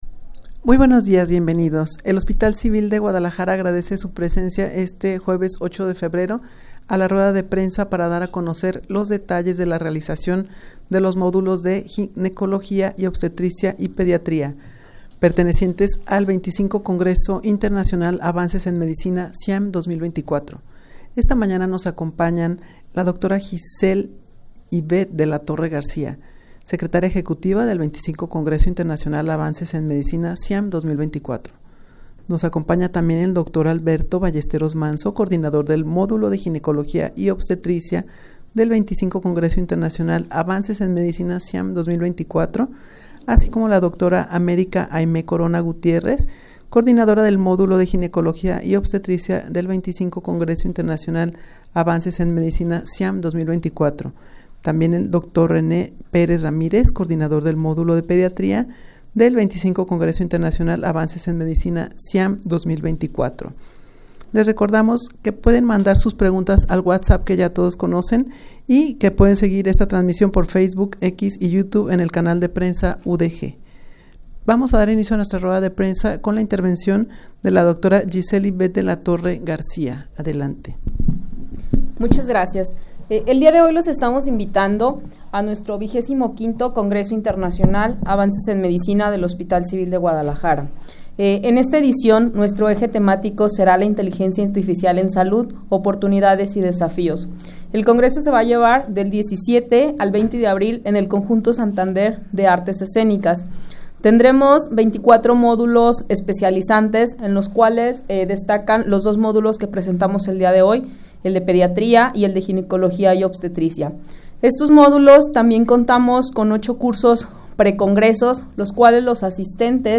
rueda-de-prensa-para-dar-a-conocer-la-realizacion-de-los-modulos-de-modulos-de-ginecologia-y-obstetricia-y-pediatria.mp3